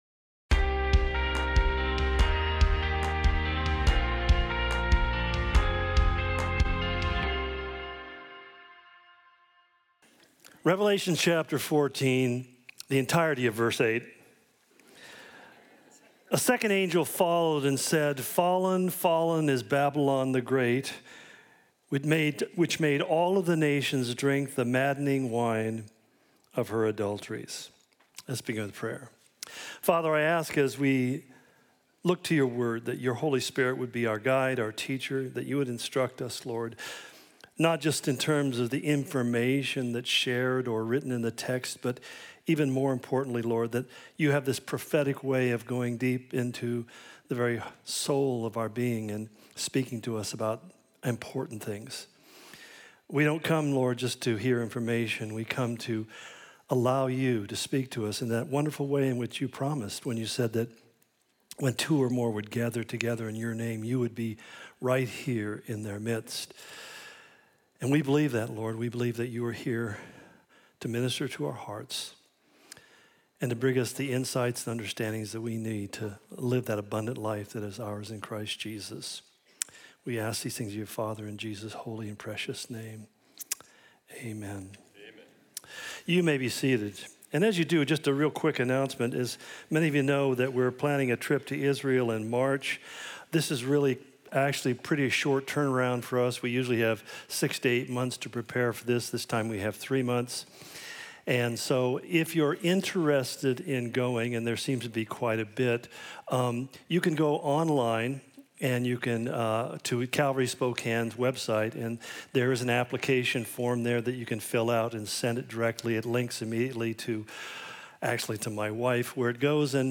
Calvary Spokane Sermon Of The Week podcast Untuk memberi Anda pengalaman terbaik, situs ini menggunakan cookie.